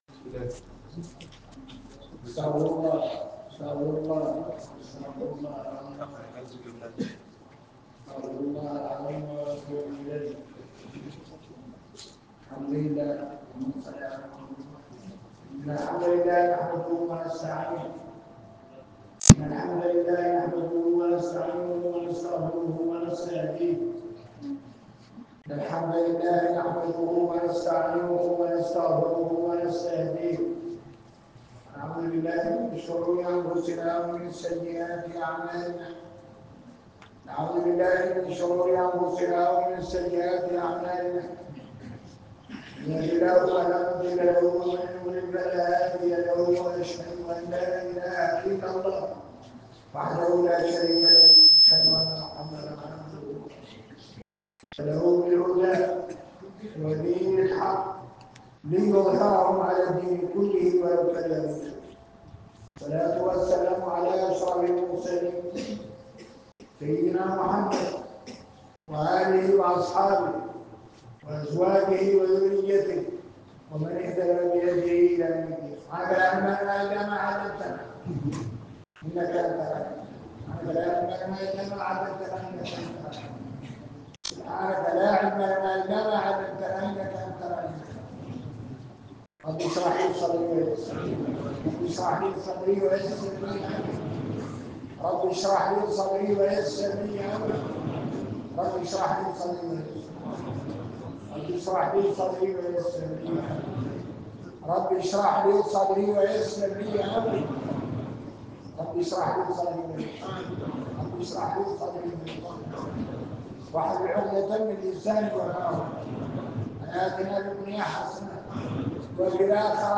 المحاضرة
في جامع الفرقان في عين الطلح